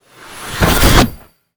magic_conjure_charge1_02.wav